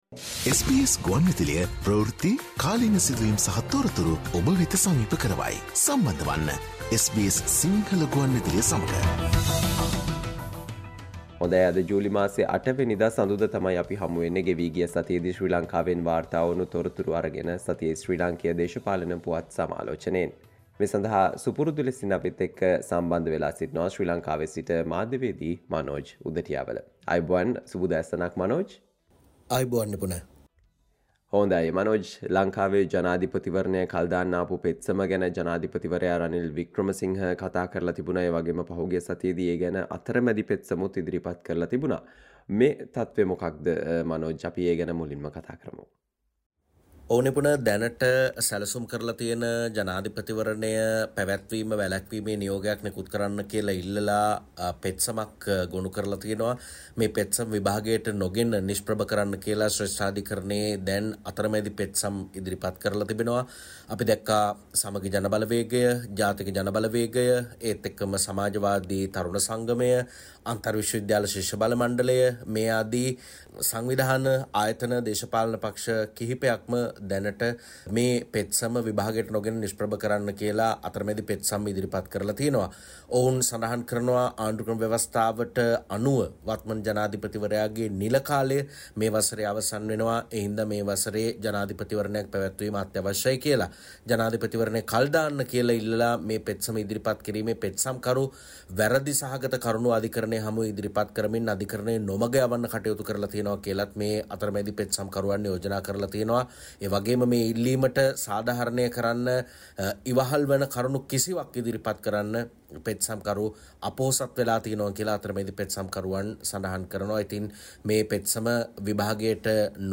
SBS Sinhala radio brings you the most prominent political news highlights of Sri Lanka in this featured Radio update on every Monday.